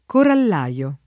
corallaio [ korall #L o ] s. m.; pl. -lai